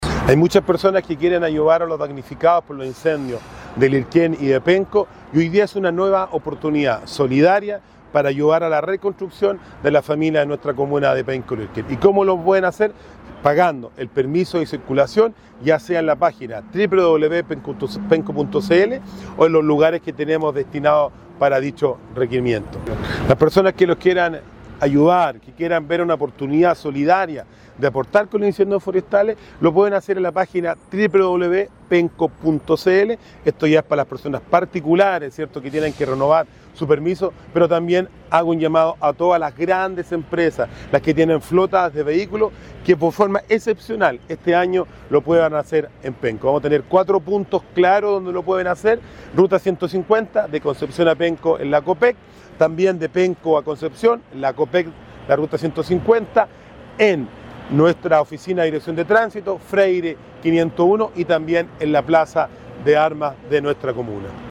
En este contexto, el alcalde Rodrigo Vera explicó que la medida busca “convertir un trámite obligatorio en una herramienta concreta para recuperar nuestra comuna”.